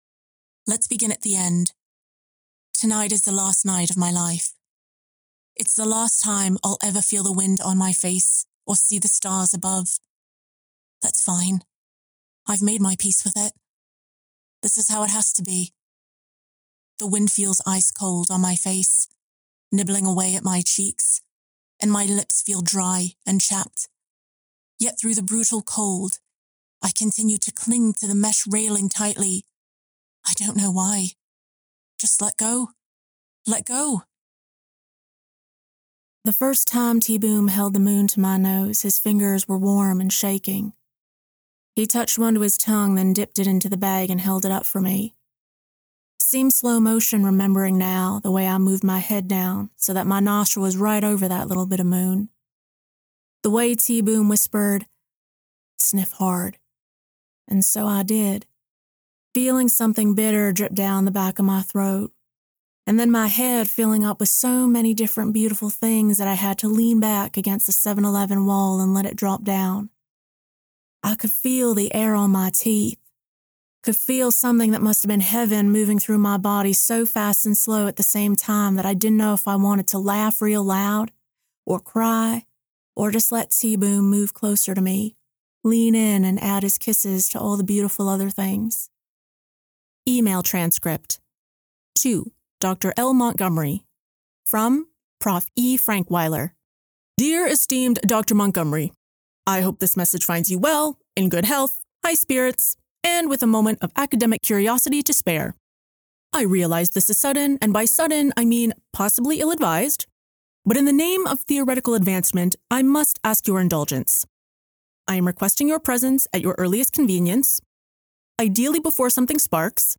5. Accent and Character Reel (British, southern, female voicing male, midwest)
I have a professional sound treated recording studio with industry standard microphones, equipment, and recording / audio editing software.